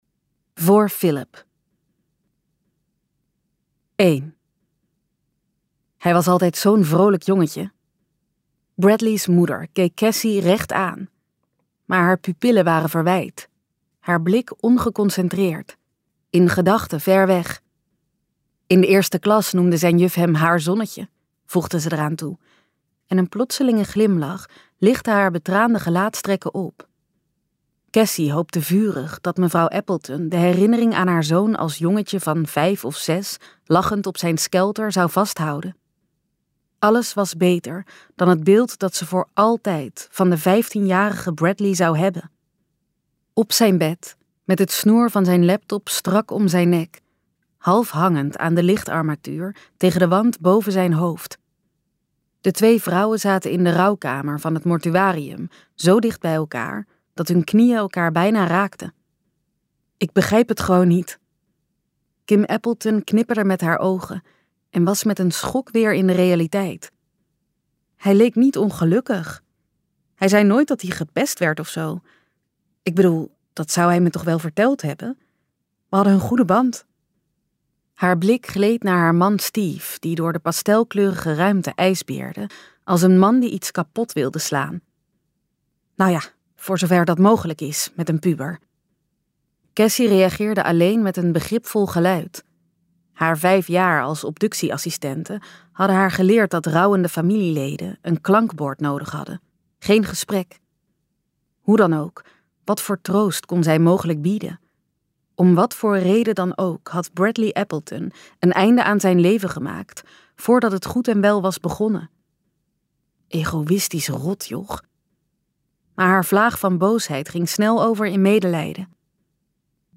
Ambo|Anthos uitgevers - Levenslang luisterboek